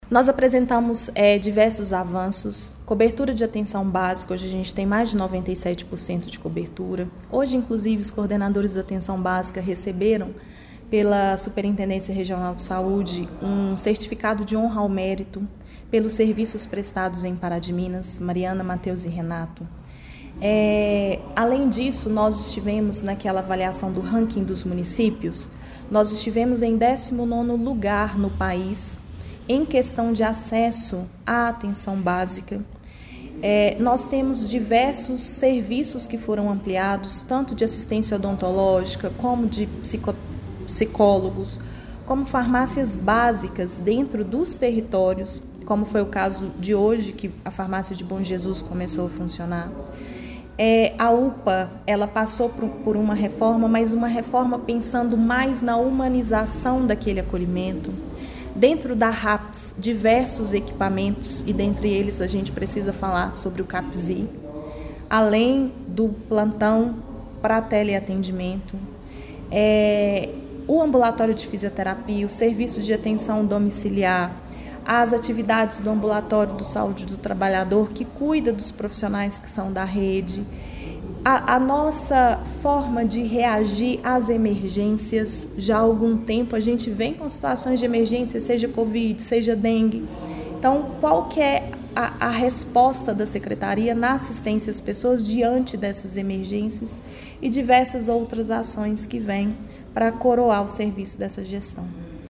A secretaria municipal de Saúde, Ana Clara Teles Meytre, falou com a reportagem do Portal GRNEWS sobre a prestação de contas apresentada através do relatório do segundo quadrimestre de 2024, mostrando avanços na saúde pública em Pará de Minas: